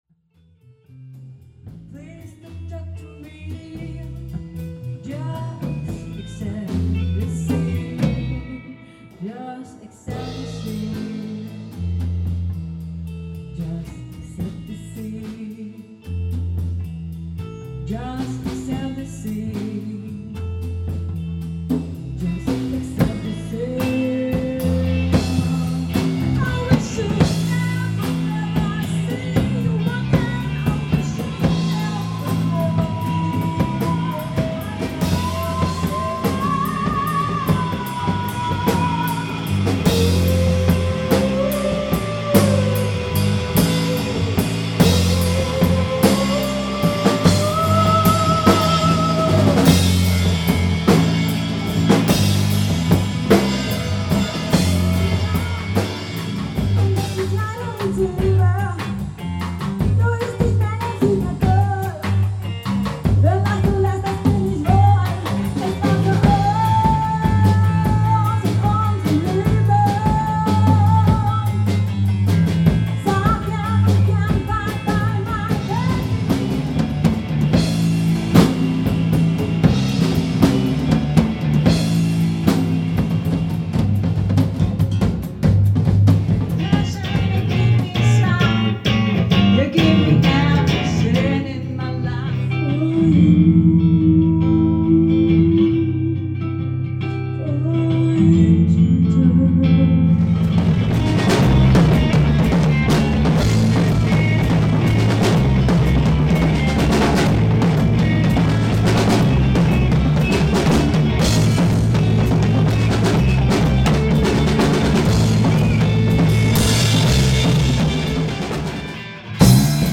Aber es ist nicht hoffnungslos, wie folgender Zusammenschnitt der Proben hoffentlich zeigt.
band-medley (mp3, 3,190 KB)